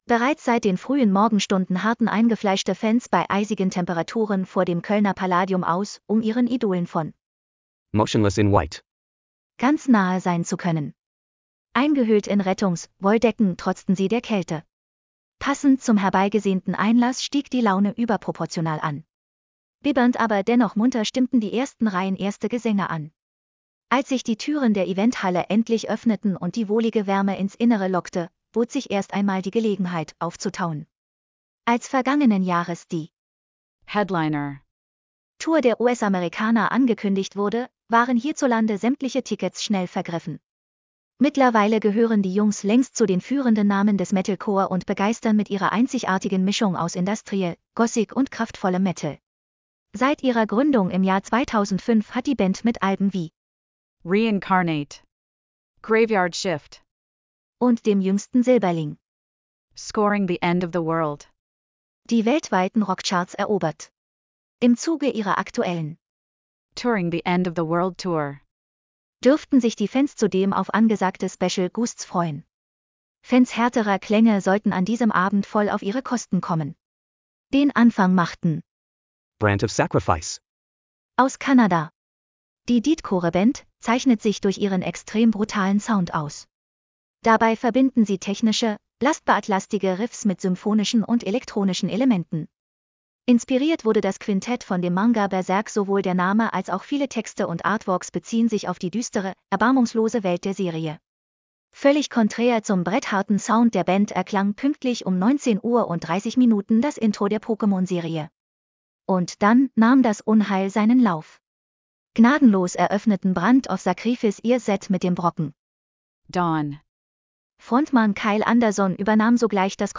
Lass Dir den Beitrag vorlesen: /wp-content/TTS/188126.mp3 Als vergangenen Jahres die Headliner-Tour der US-Amerikaner angekündigt wurde, waren hierzulande sämtliche Tickets schnell vergriffen.